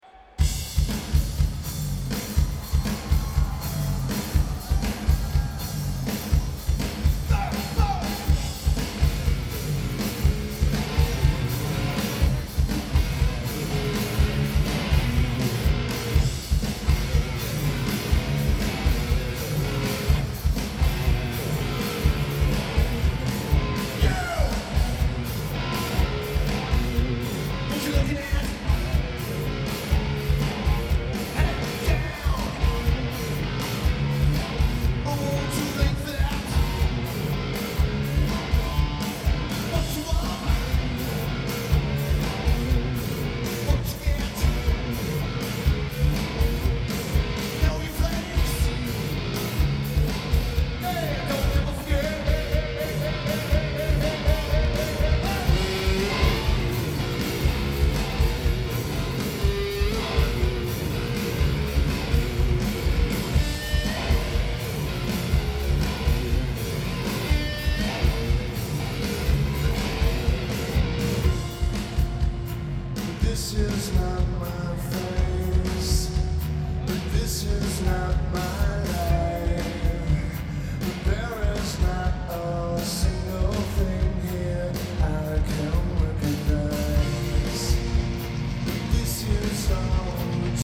Ford Amphitheatre
Lineage: Audio - AUD (Zoom H2)